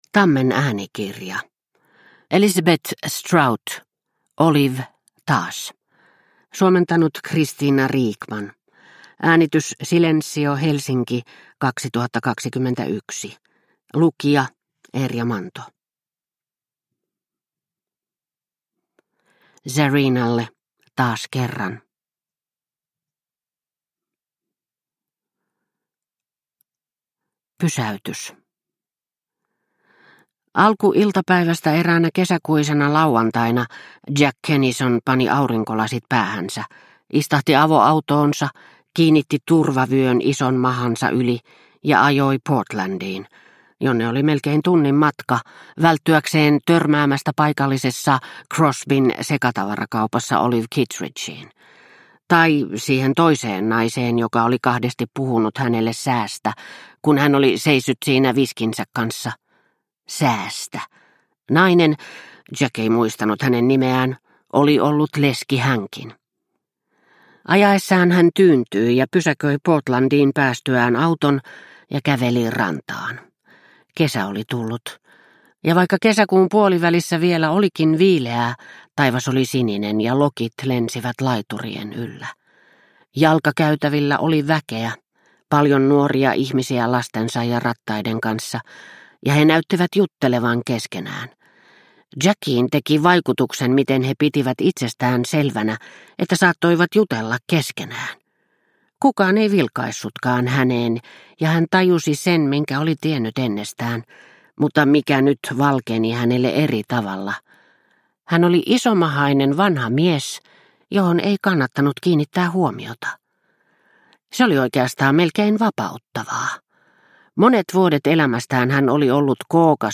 Olive, taas – Ljudbok – Laddas ner